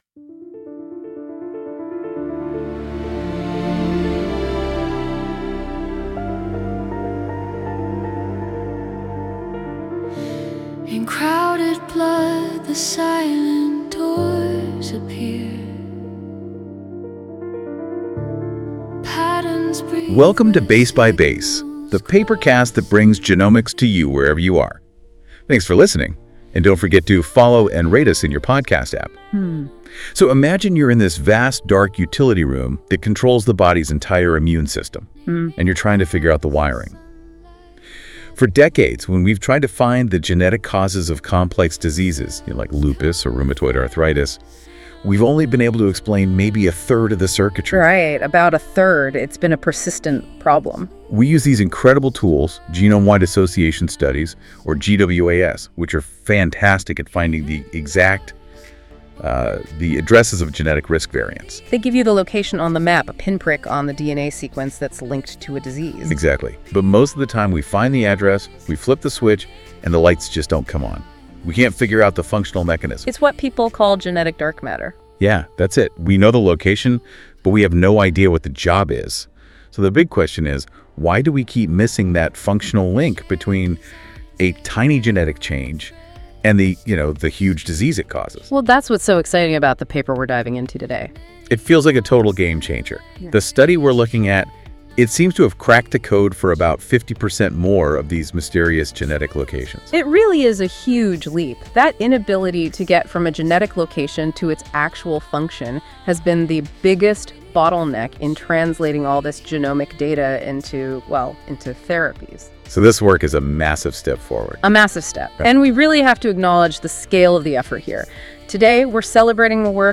Impact of Chromatin Accessibility QTLs Across Immune Contexts Music:Enjoy the music based on this article at the end of the episode.